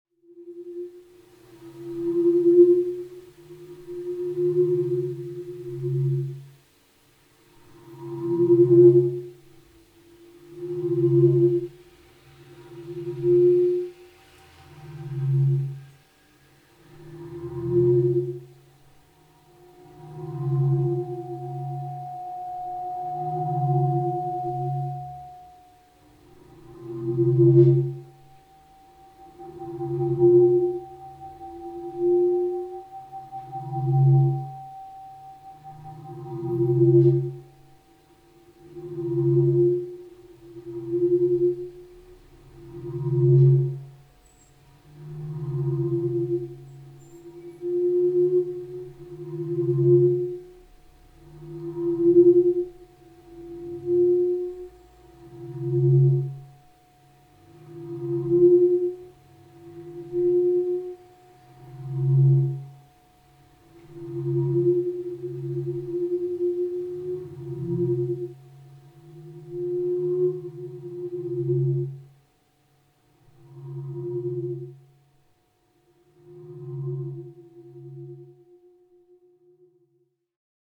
Today a test installation has been running for some hours in my studio.
This working version is a double system based on three microphones in the room and two speakers, each fed from different mics individually governed by a noise gate.  The two systems are interdependent, working around different frequency areas when the room is empty.
testing in DC studio
In smaller spaces each tiny variable has a disproportionate effect, in particular high frequency content in the feedback caused by reflections from gallery wall surfaces can become a very uncomfortable distraction from any sense of the overall space.
installation test 8feb15.mp3